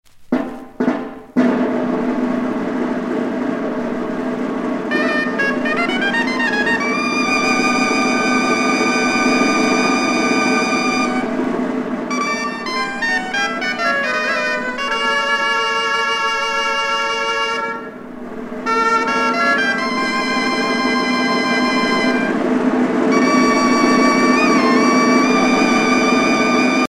Danses navarraises
Pièce musicale éditée